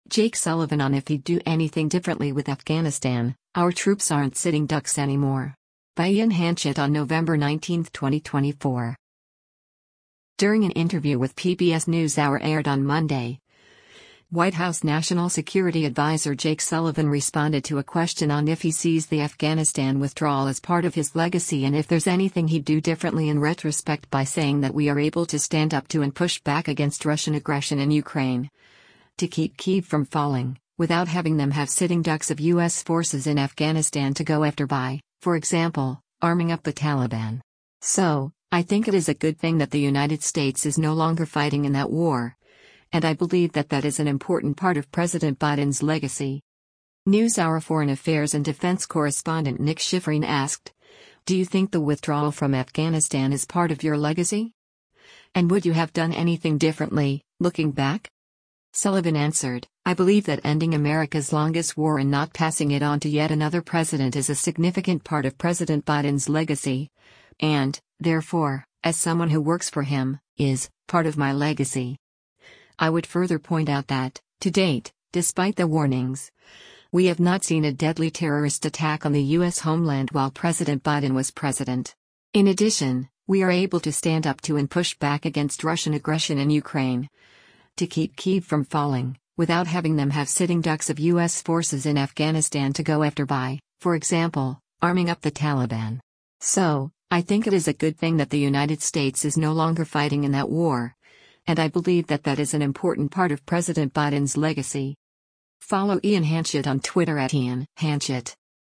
During an interview with “PBS NewsHour” aired on Monday, White House National Security Adviser Jake Sullivan responded to a question on if he sees the Afghanistan withdrawal as part of his legacy and if there’s anything he’d do differently in retrospect by saying that “we are able to stand up to and push back against Russian aggression in Ukraine, to keep Kyiv from falling, without having them have sitting ducks of U.S. forces in Afghanistan to go after by, for example, arming up the Taliban. So, I think it is a good thing that the United States is no longer fighting in that war, and I believe that that is an important part of President Biden’s legacy.”